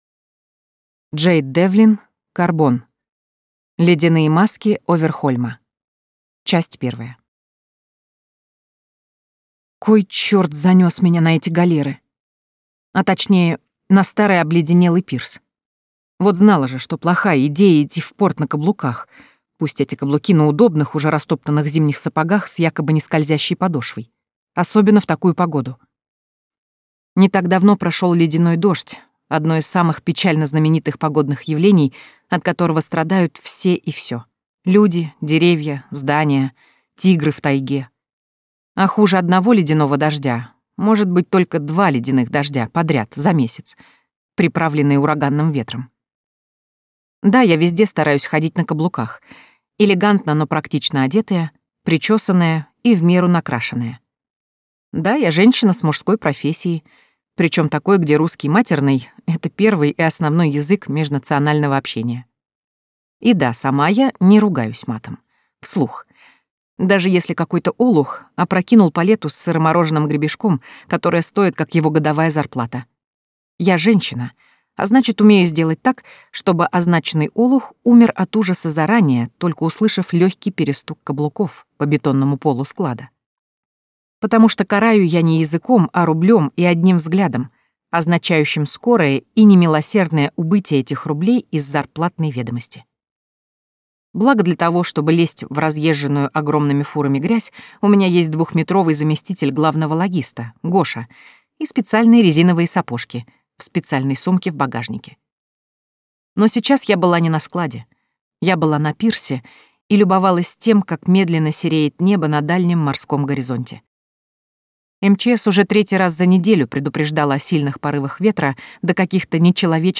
Ярроу (слушать аудиокнигу бесплатно) - автор Айлин Лин